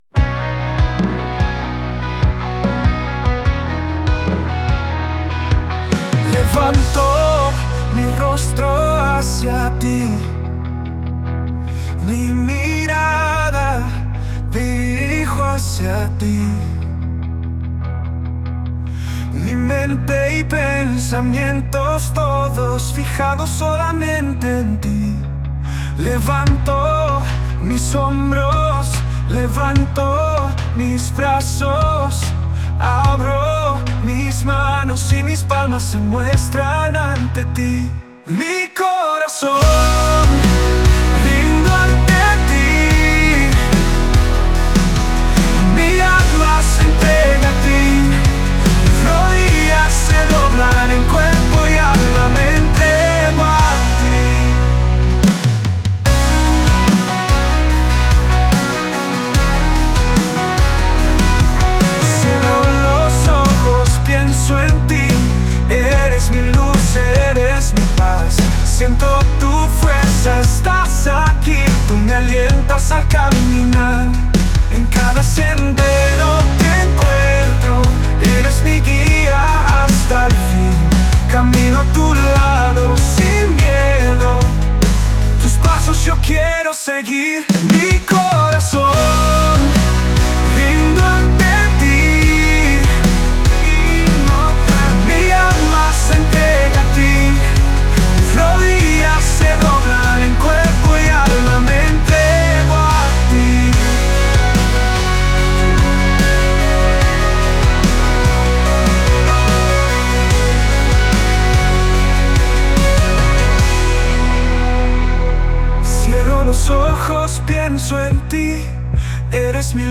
Rock Suave